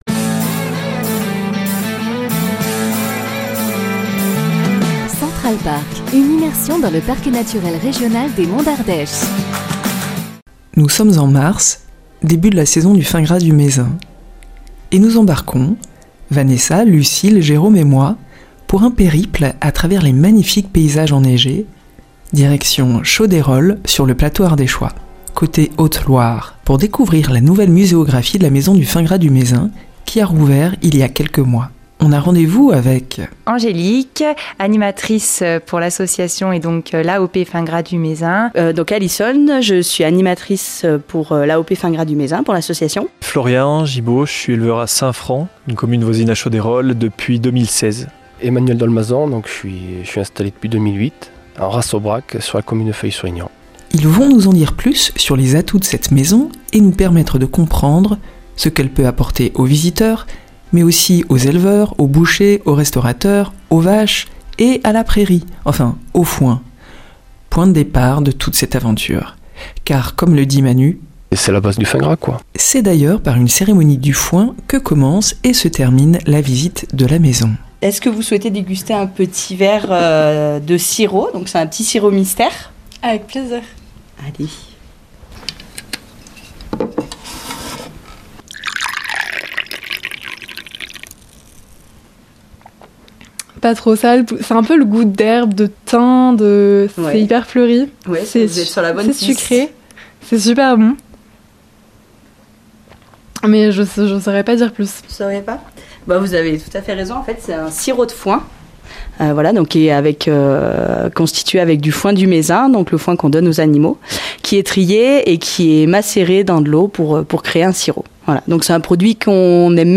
et deux éleveurs de Fin gras du Mézenc.